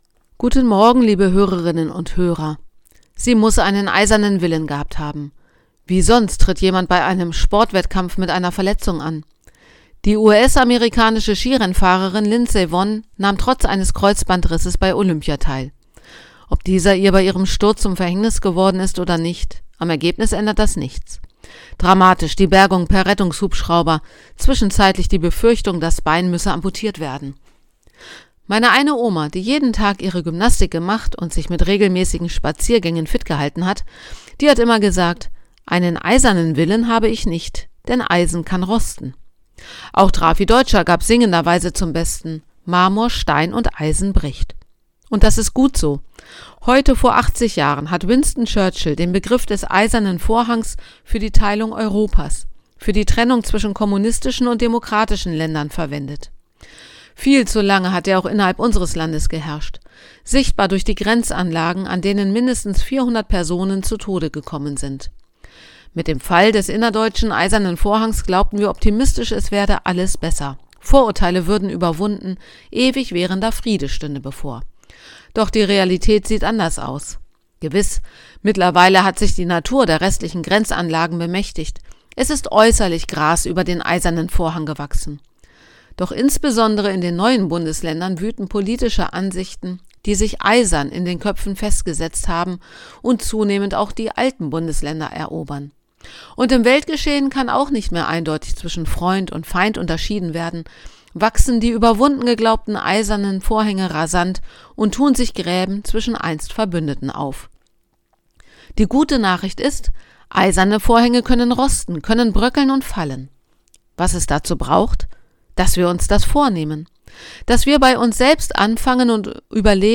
Radioandacht vom 5. März